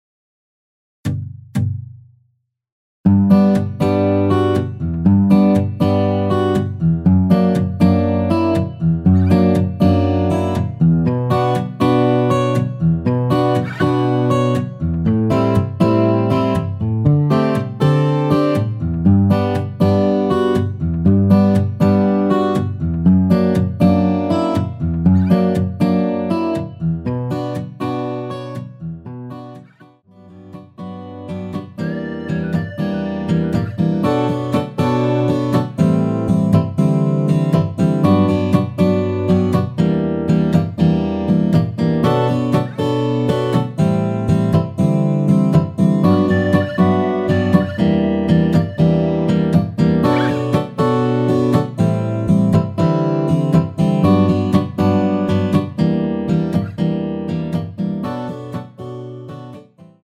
전주 없는 곡이라 전주 2박 만들어 놓았습니다.~
앞부분30초, 뒷부분30초씩 편집해서 올려 드리고 있습니다.
중간에 음이 끈어지고 다시 나오는 이유는